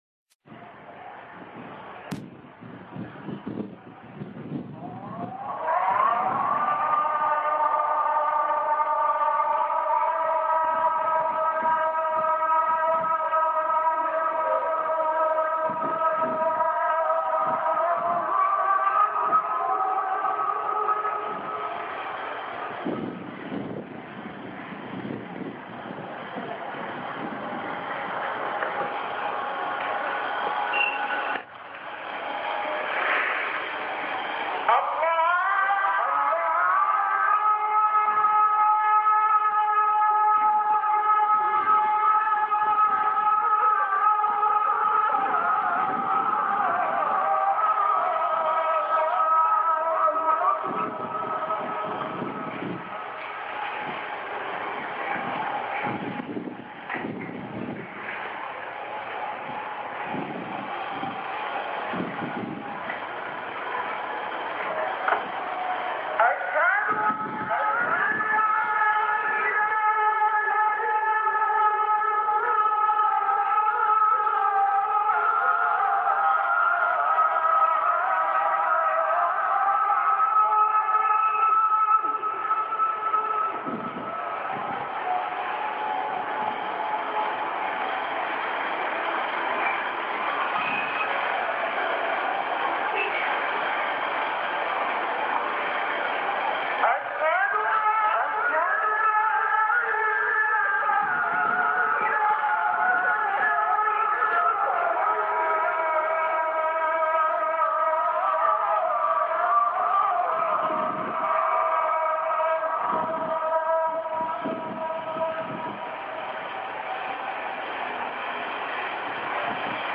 المكان: المسجد النبوي الشيخ
أذان